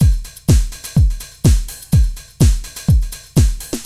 Index of /musicradar/retro-house-samples/Drum Loops
Beat 19 Full (125BPM).wav